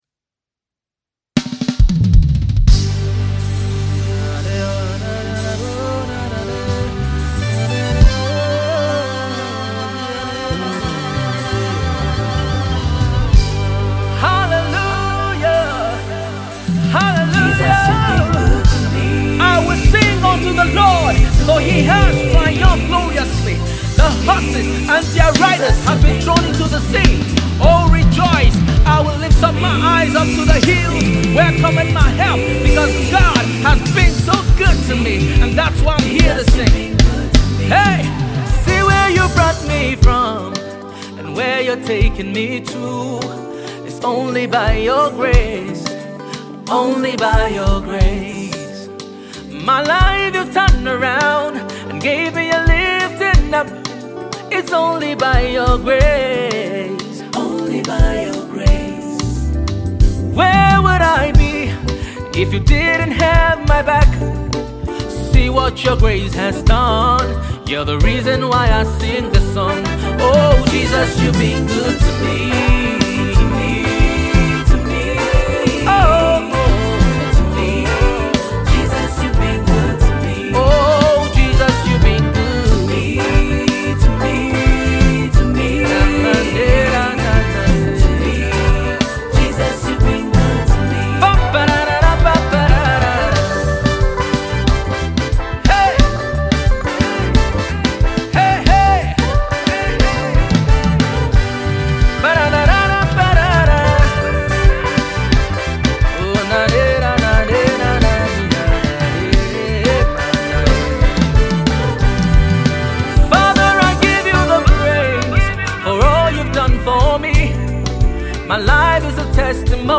Urban gospel music maestro
Tags: Gospel Music,  Lyrics,